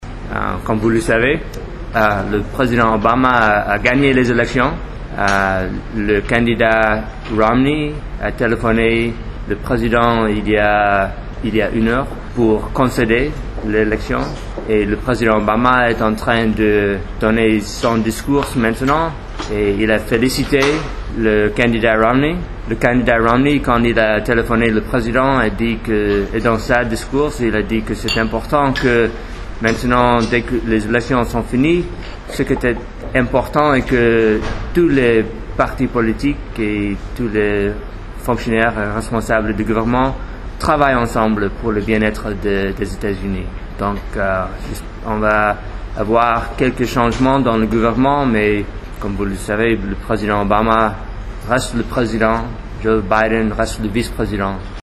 Discours de l'ambassadeurs des USA à Dkr I.mp3 (856.73 Ko)
» Son Excellence M. Lewis Lukens, Ambassadeur des USA au Sénégal, vient de faire face à la presse nationale pour extérioriser les nouvelles directives diplomatiques de son pays, juste après le discours de victoire du démocrate Barack Obama. Pour lui, il y aura juste un léger changement dans le gouvernement, mais la politique de la première puissance mondiale reste la-même.